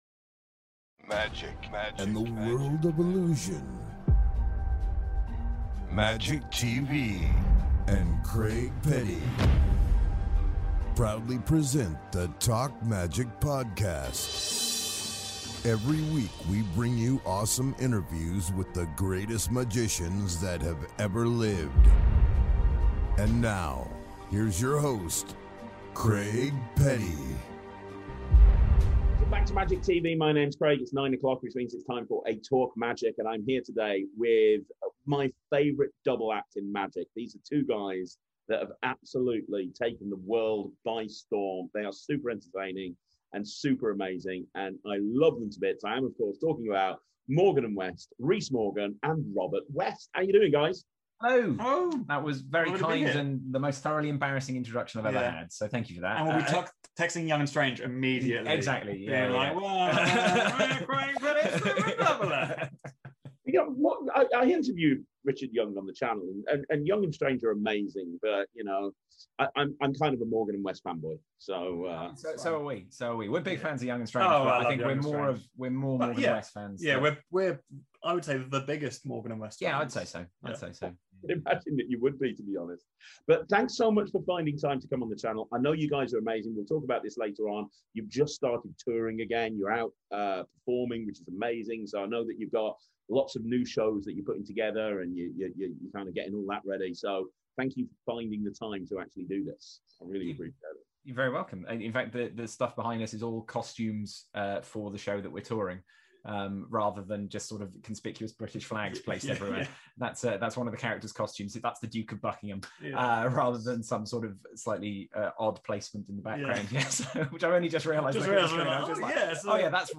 In this interview they open up about their career.